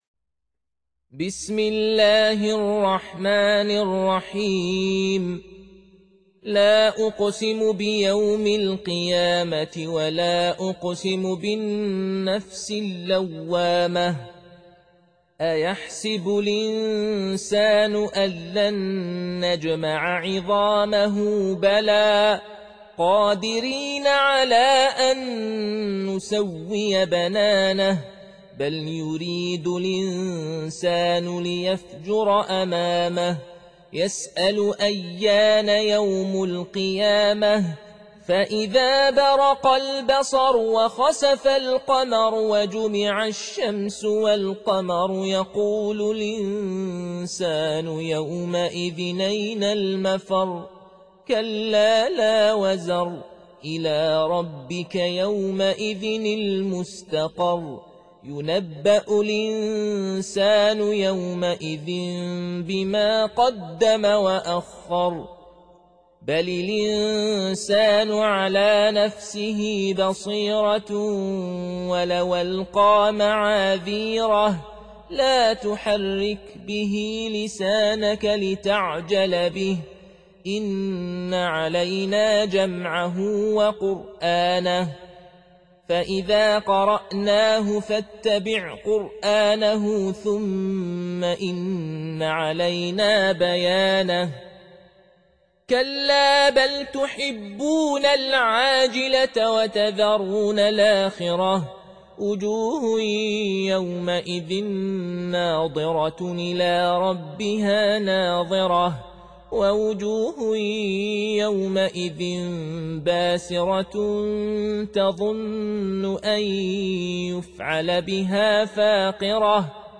Surah Sequence تتابع السورة Download Surah حمّل السورة Reciting Murattalah Audio for 75. Surah Al-Qiy�mah سورة القيامة N.B *Surah Includes Al-Basmalah Reciters Sequents تتابع التلاوات Reciters Repeats تكرار التلاوات